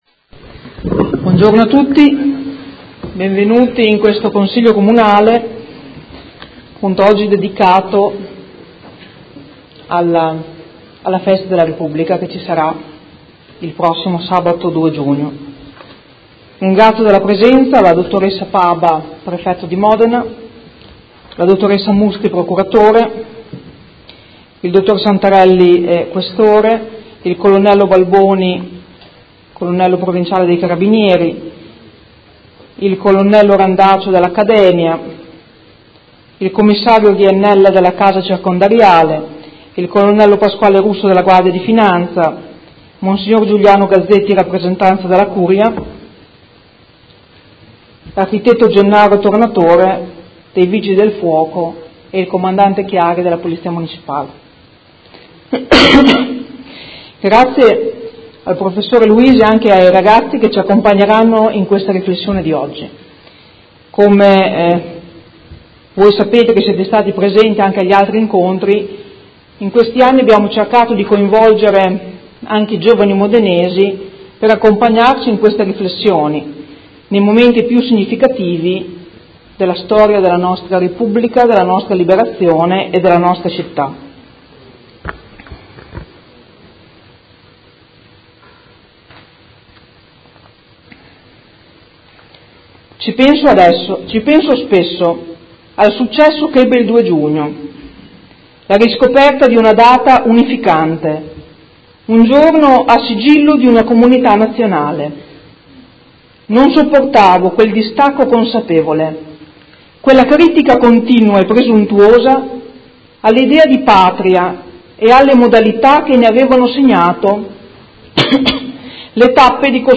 Seduta del 31/05/2018 Festa della Repubblica